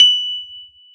Index of /90_sSampleCDs/Keyboards of The 60's and 70's - CD2/PNO_E.Grand/PNO_E.Grand